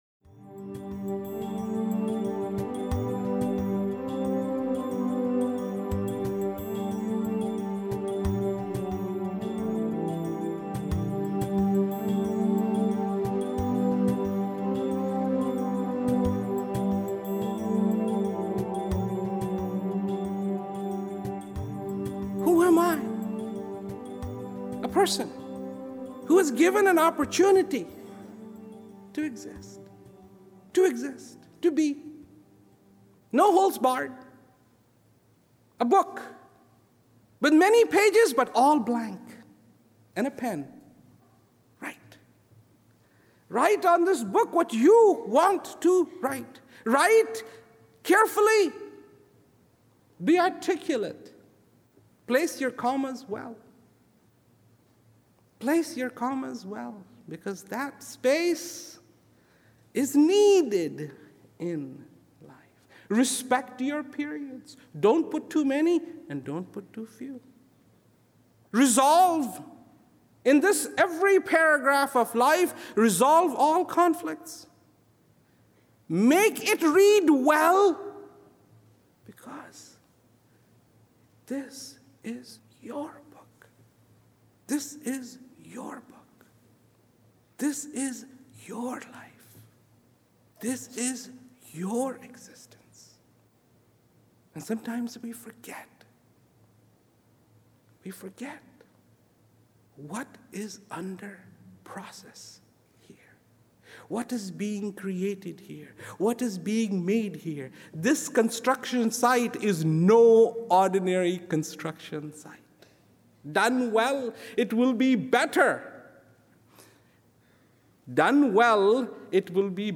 Transcripts of Rawat's speeches cannot do justice to Rawat's astonishing delivery, they have to be heard to be appreciated. Mp3 copies of these excerpts are recorded at high quality (256Kbps) to ensure no whispered nuance or frenzied climax is missed.